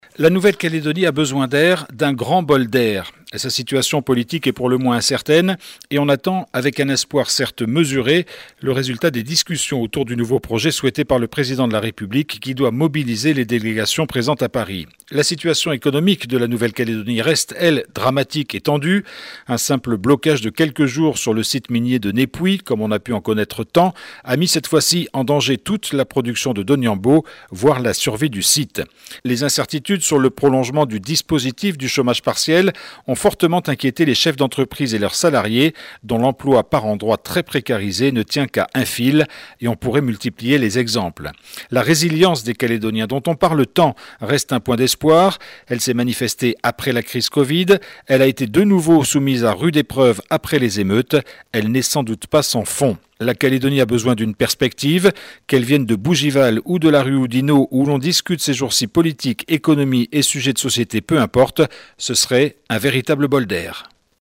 LE BILLET D'HUMEUR